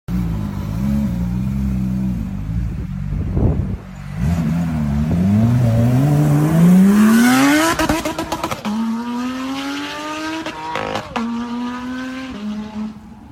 If you love engine sounds sound effects free download
Enjoy videos of V6, V8, V12, rotary engine and many more tuned vehicles.